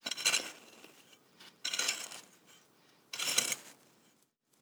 Babushka / audio / sfx / Farming / SFX_Harke_01.wav
SFX_Harke_01.wav